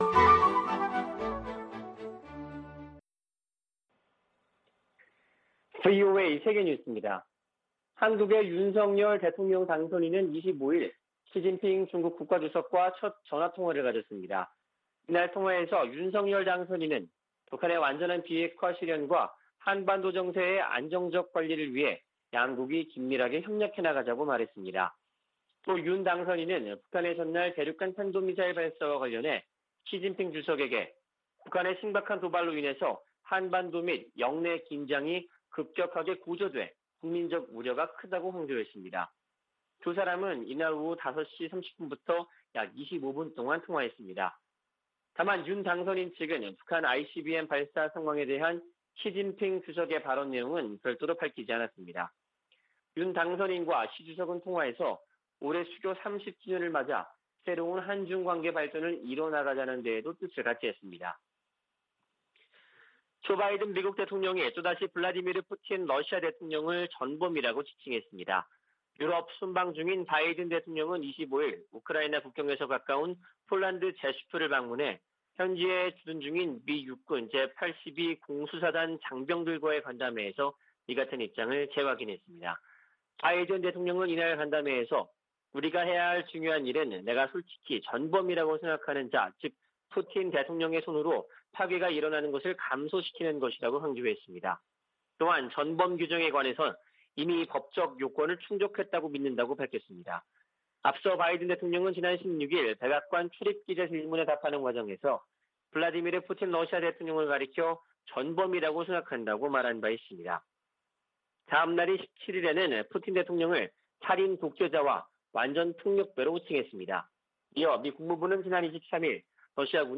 VOA 한국어 아침 뉴스 프로그램 '워싱턴 뉴스 광장' 2022년 3월 26일 방송입니다. 북한은 24일 발사한 탄도미사일이 신형 ICBM인 '화성-17형'이라며 시험발사에 성공했다고 밝혔습니다. 미국 정부는 북한이 ICBM으로 추정되는 장거리 탄도미사일을 발사한 데 강력한 규탄 입장을 밝혔습니다. 미국 정부가 북한 미사일 프로그램에 연관된 북한과 러시아의 개인과 회사들을 제재했습니다.